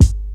• Old School Loud Hip-Hop Kick Sample G Key 347.wav
Royality free kick drum sound tuned to the G note. Loudest frequency: 383Hz
old-school-loud-hip-hop-kick-sample-g-key-347-5PE.wav